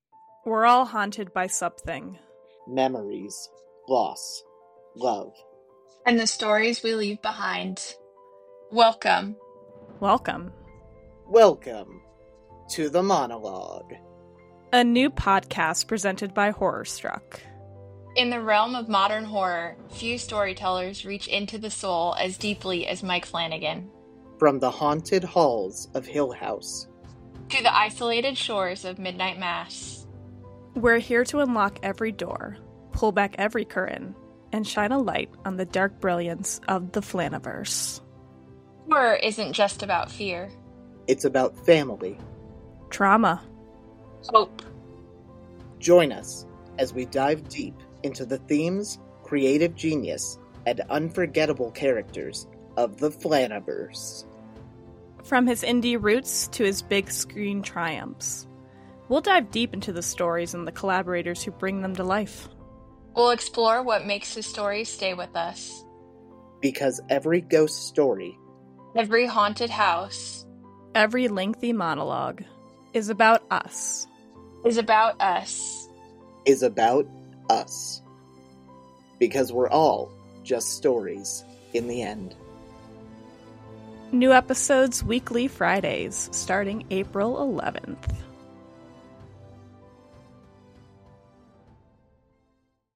A thematic deep dive into the works of Mike Flanagan, exploring recurring motifs, creative processes, and collaborations in the Flanaverse. Featuring roundtable discussions, guest appearances, and a focus on his unique voice in modern horror.